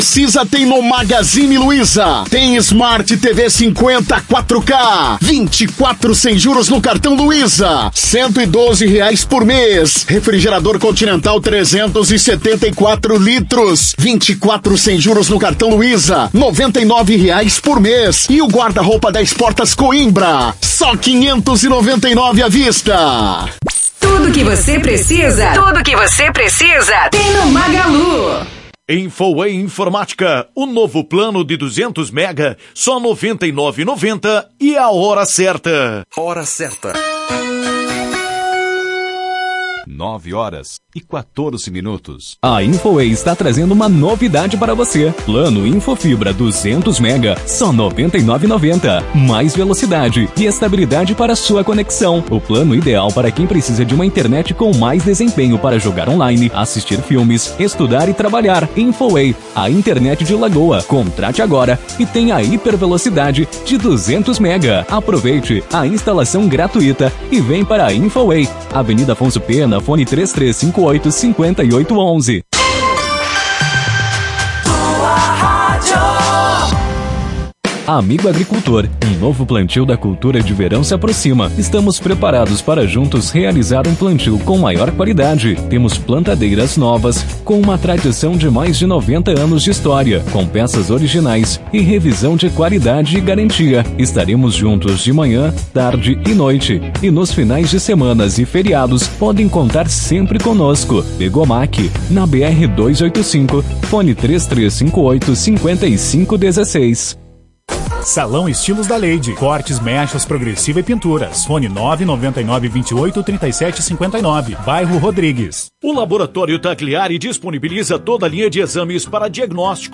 Em entrevista à Tua Rádio Cacique na manhã desta quarta-feira